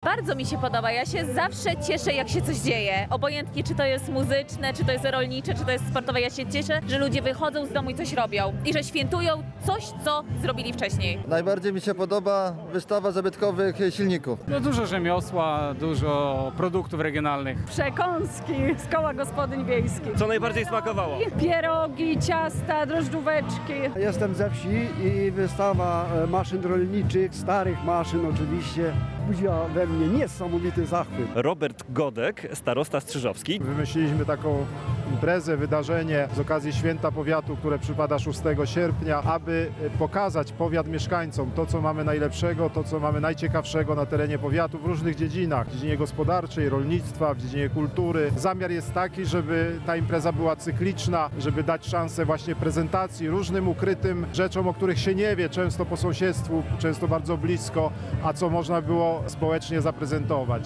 W Wiśniowej w powiecie strzyżowskim odbyła się „Agromania”. To wydarzenie, które promuje lokalne produkty rolnicze, kulturę wiejską oraz dorobek mieszkańców powiatu.